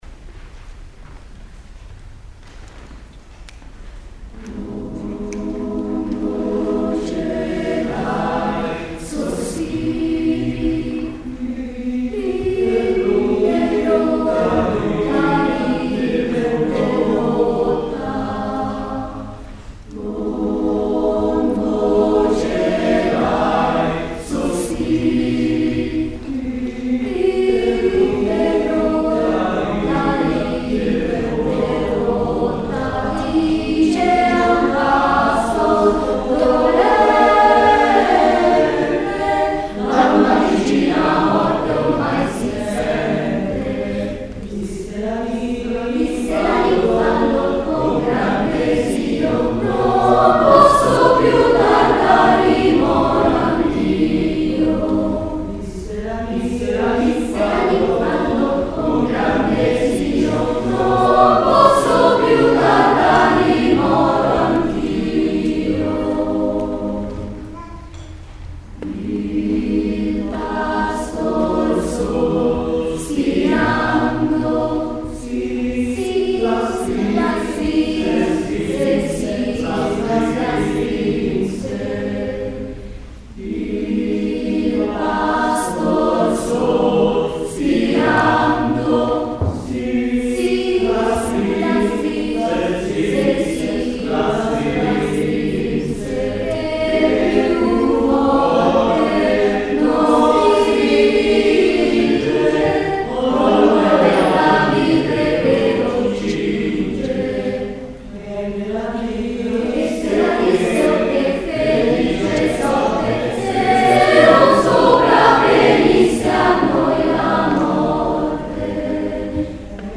Concerto di Primavera
Sala Maestra ~ Palazzo Chigi
Coro in Maschera
Eseguiti in Ensamble